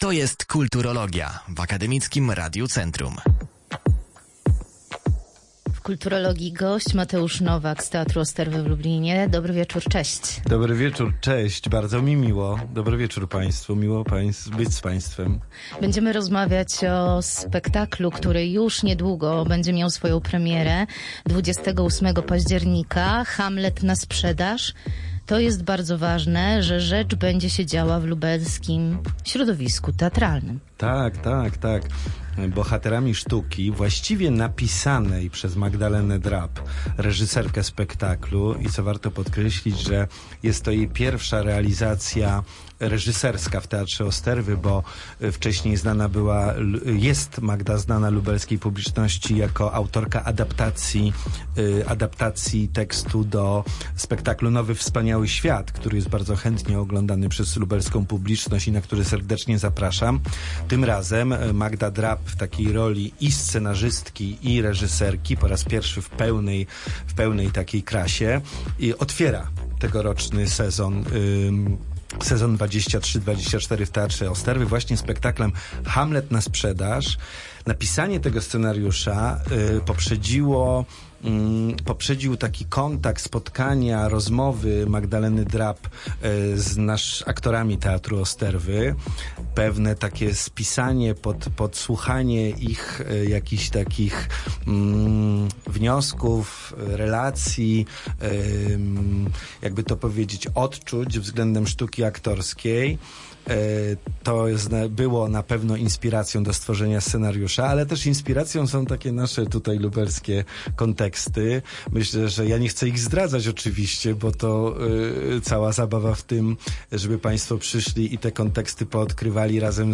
Przypominam rozmowę